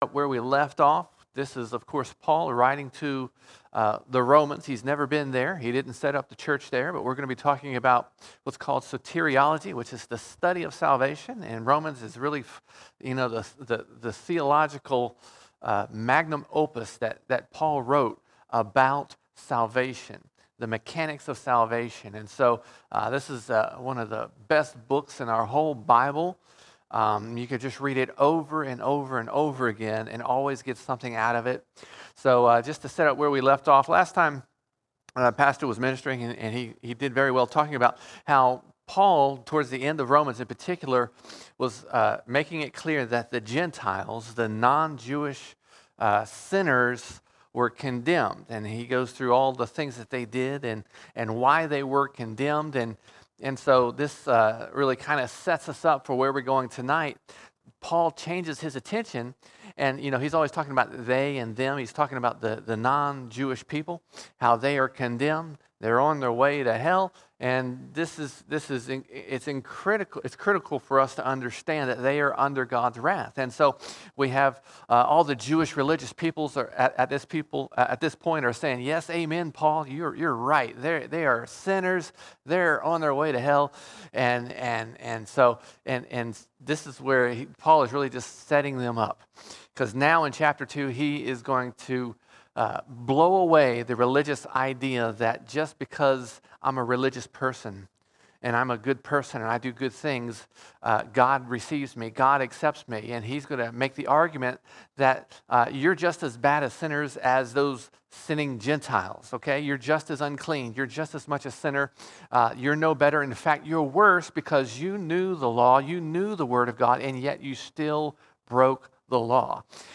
29 June 2023 Series: Romans All Sermons Romans 2:1 to 2:20 Romans 2:1 to 2:20 Paul makes the case in Romans 2 that the religious Jew is just as lost as the heathen sinner described in chapter 1.